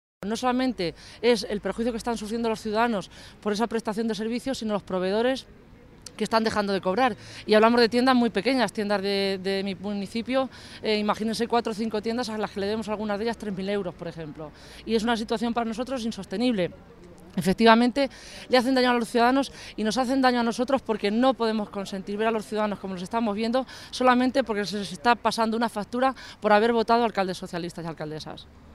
Así lo ponían de manifiesto todas las alcaldesas que atendían en Toledo esta mañana a los medios, que señalaban que llevan un año y medio sin cobrar un euro en las partidas que la Junta debe financiar y que son esenciales para sus vecinos, porque afectan a las políticas sociales.
Audio alcaldesa de Carcelen